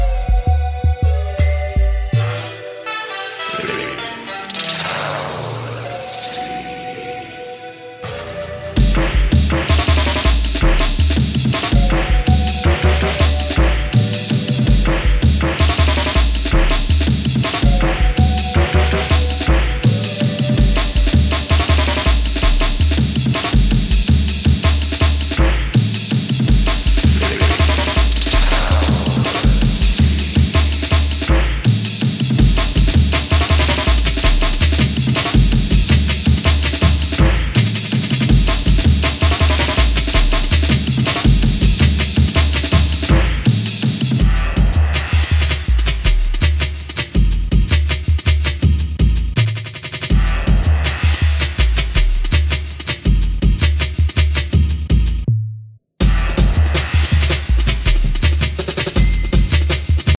Think breaks
koto